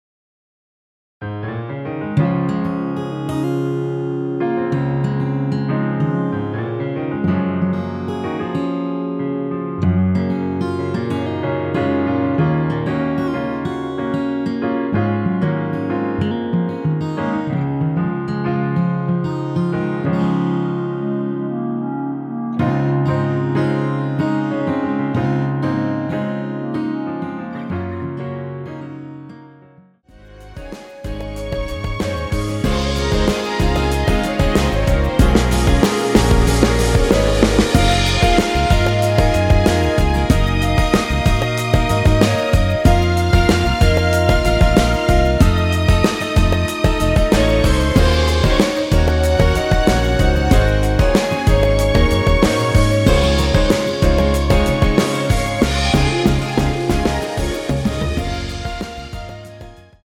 원키 멜로디 포함된 MR입니다.
Db
앞부분30초, 뒷부분30초씩 편집해서 올려 드리고 있습니다.
중간에 음이 끈어지고 다시 나오는 이유는